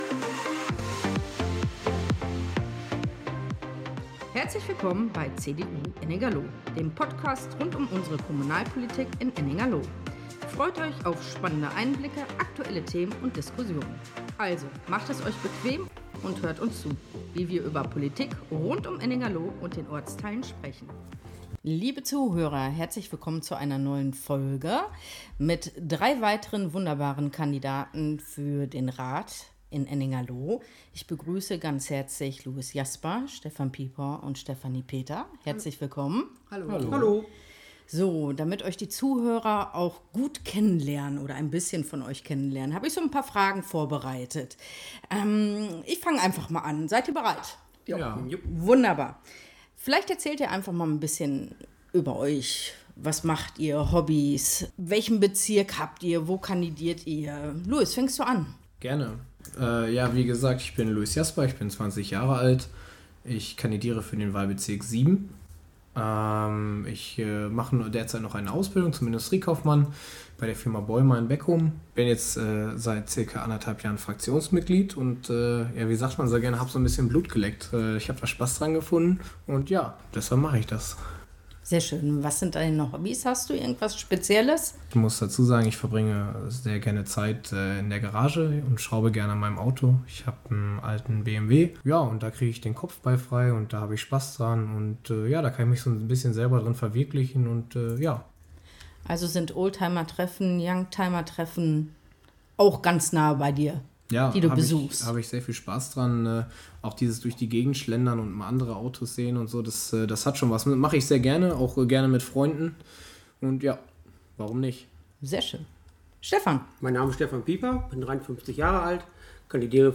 Lively-Instrumental Intro und Outro